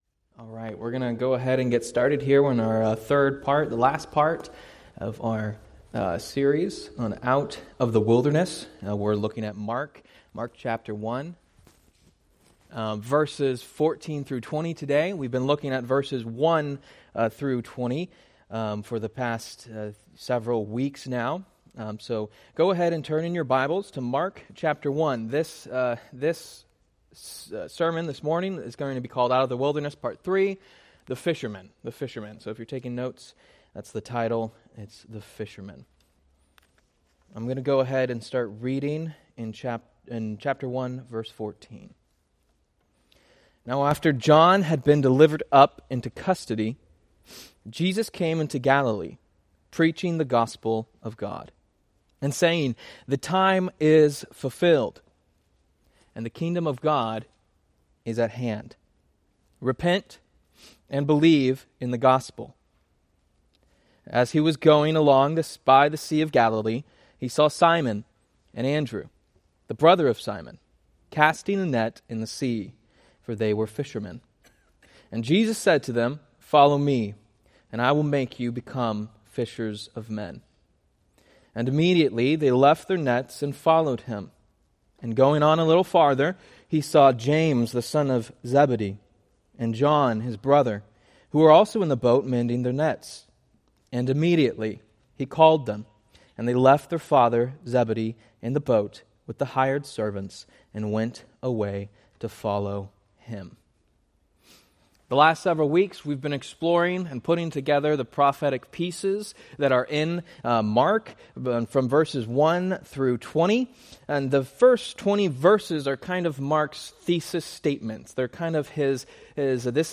Date: Oct 12, 2025 Series: Various Sunday School Grouping: Sunday School (Adult) More: Download MP3 | YouTube